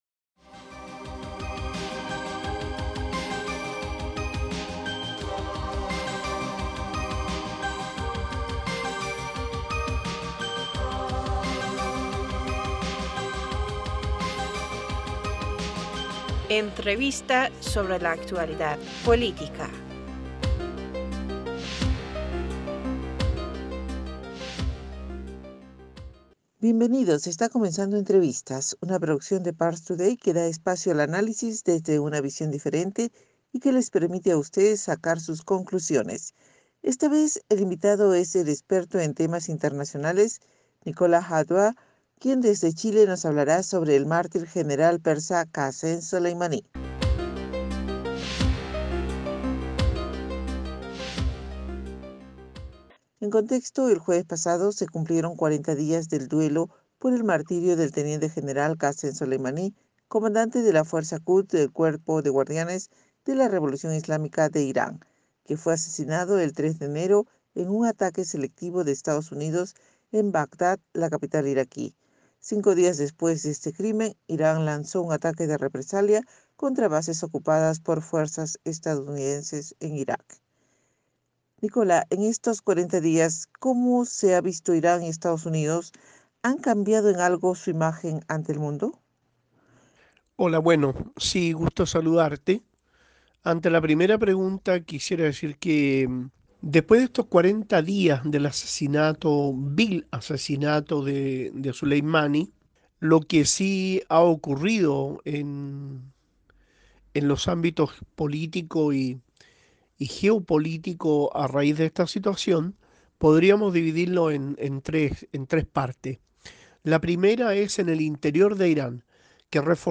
Está comenzando entrevistas.